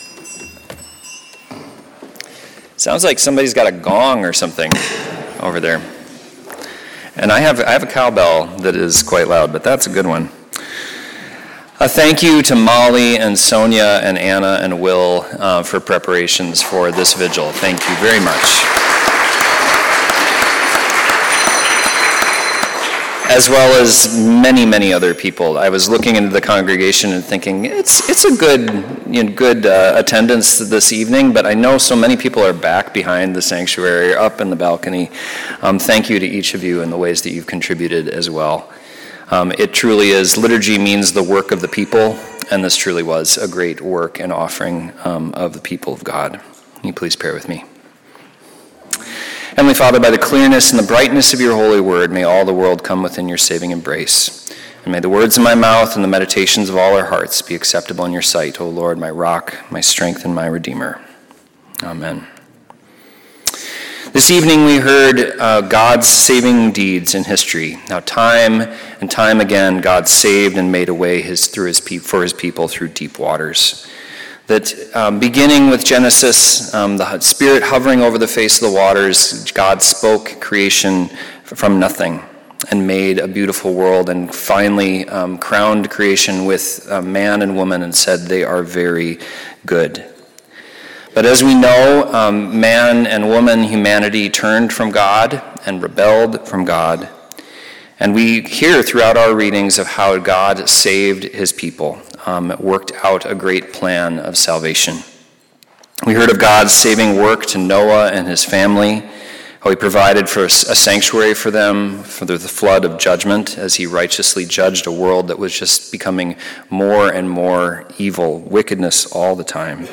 Easter Vigil–April 4, 2026
Sermons